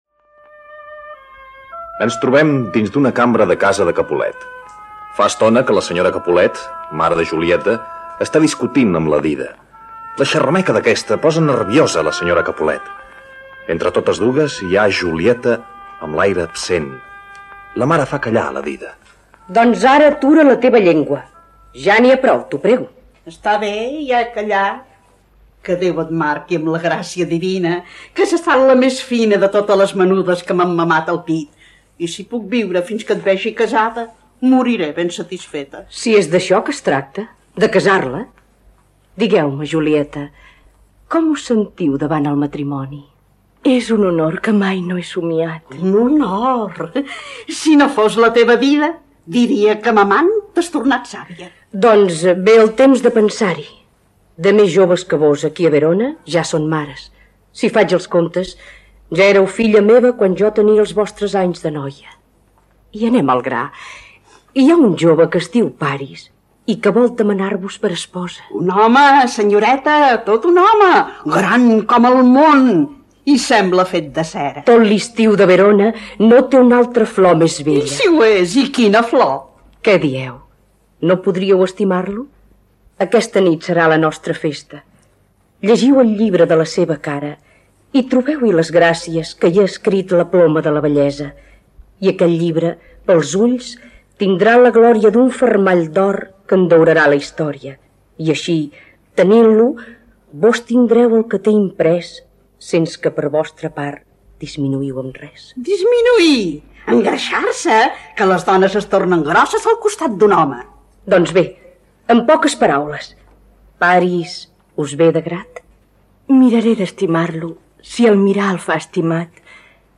Comença el 1949 com actriu del quadre escènic de Radio Nacional, al Teatro Invisible, on va interpretar diverses obres dramàtiques i també contes infantils.
Teatro invisile: Romeo i Julieta (Senyora Capulet)- RNE, anys 60